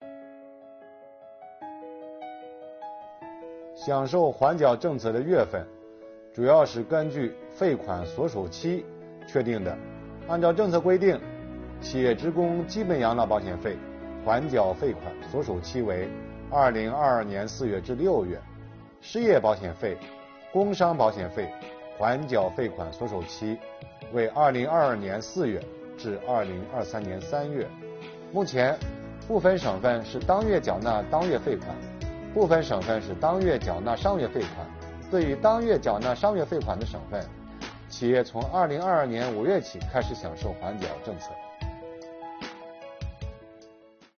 本期课程由国家税务总局社会保险费司副司长王发运担任主讲人，对公众关注的特困行业阶段性缓缴企业社保费政策问题进行讲解。